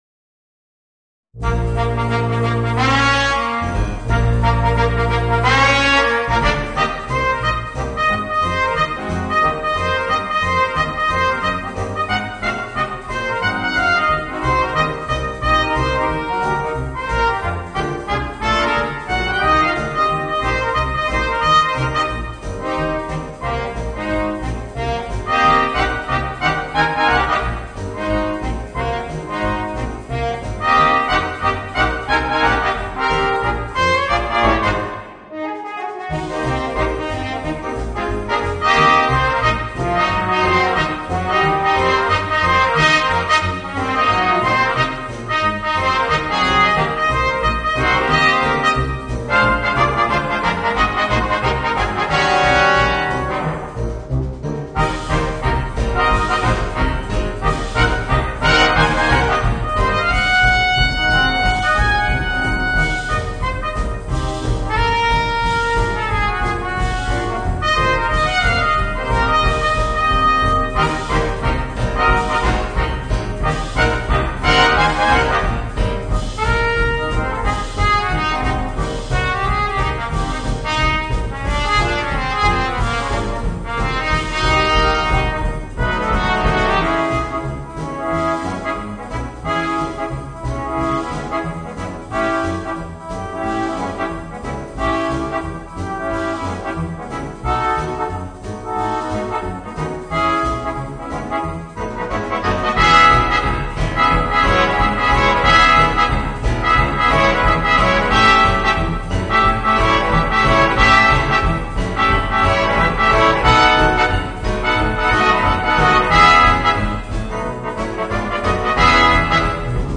Voicing: 2 Trumpets, Trombone, Euphonium and Tuba